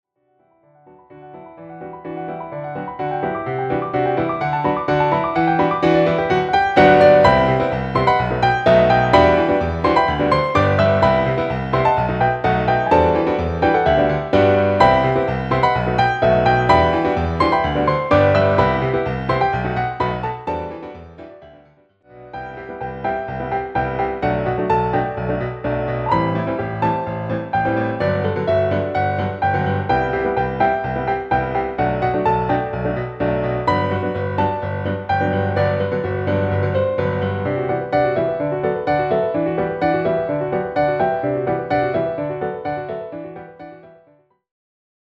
ピアノ連弾試聴